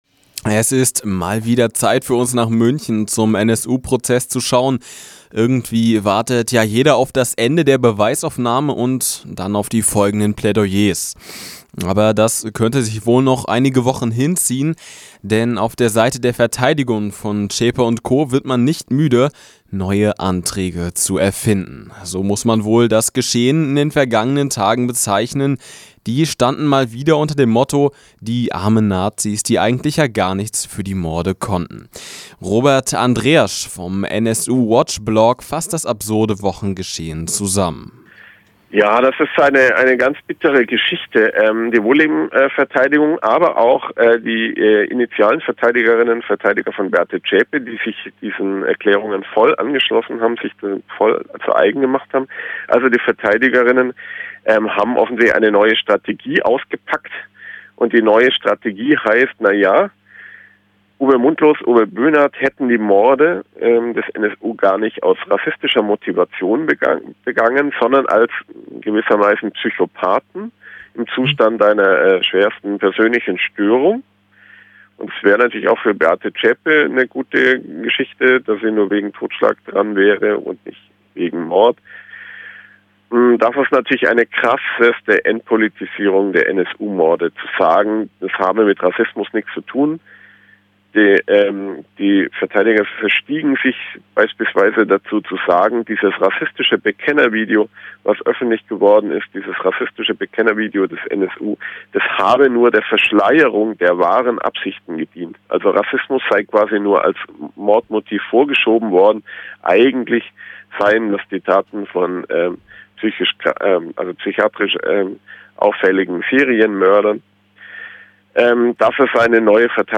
Radio zum Nachhören